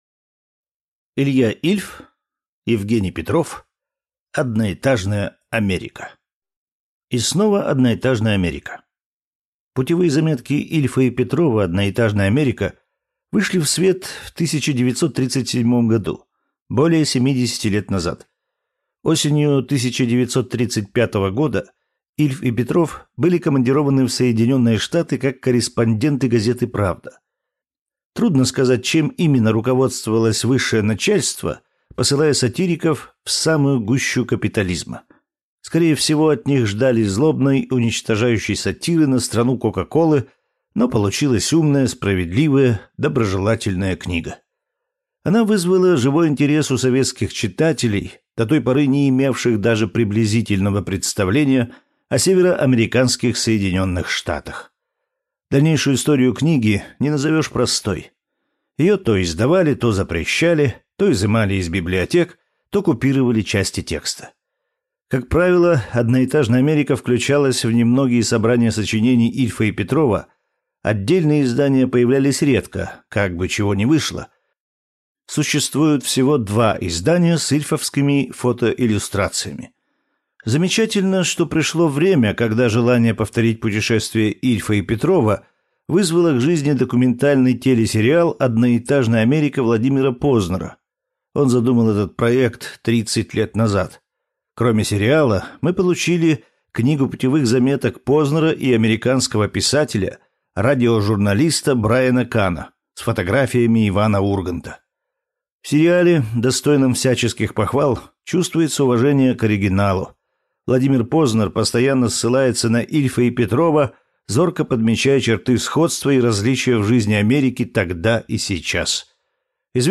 Аудиокнига Одноэтажная Америка | Библиотека аудиокниг